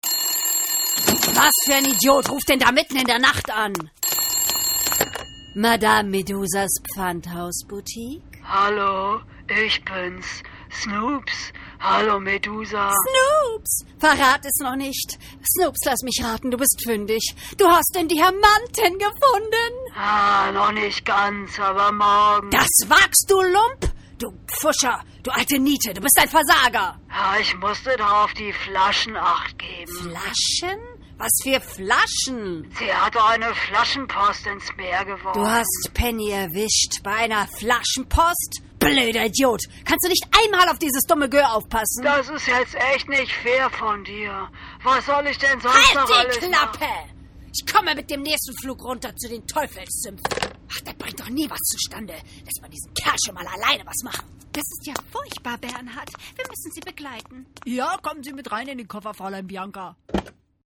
Schauspielerin, Sprecherin
Sprechprobe: Industrie (Muttersprache):